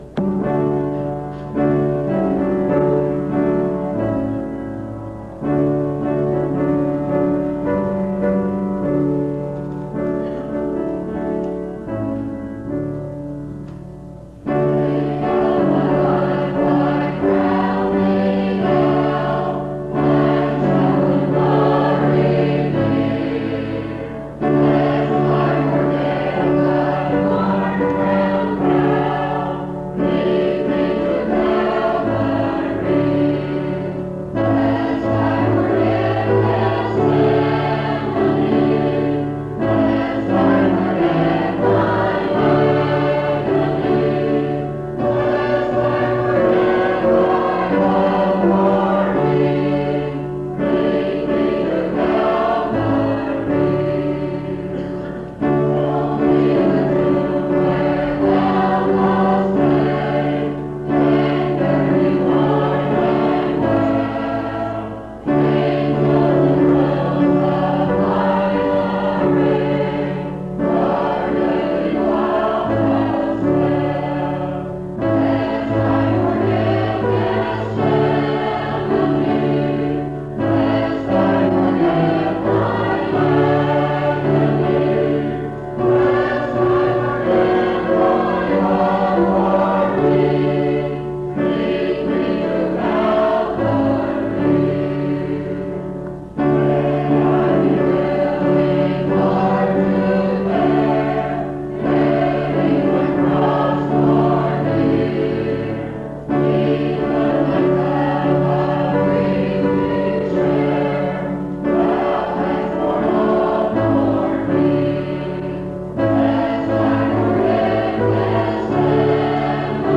Lead Me to Calvary Item b9e6028bc4dc93d4d87529c64f466bbedce5b320.mp3 Title Lead Me to Calvary Creator Bretheren Church Choir Description This recording is from the Monongalia Tri-District Sing.